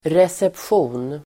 Ladda ner uttalet
Uttal: [resepsj'o:n]